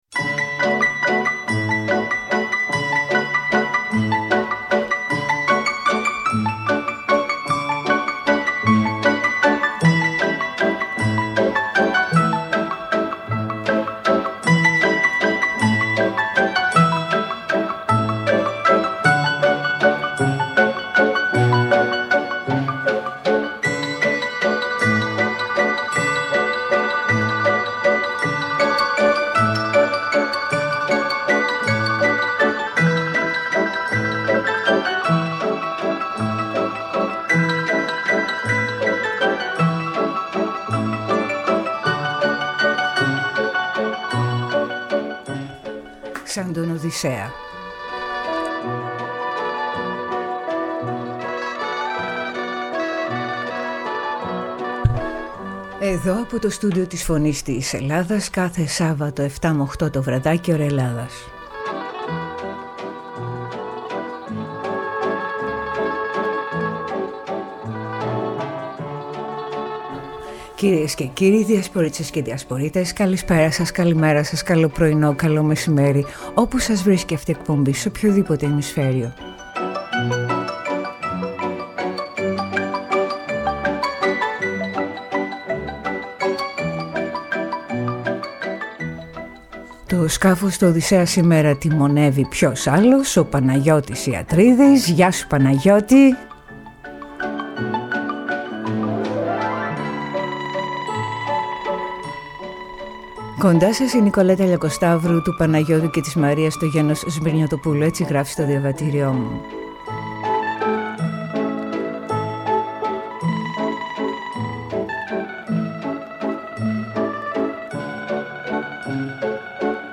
κουβέντα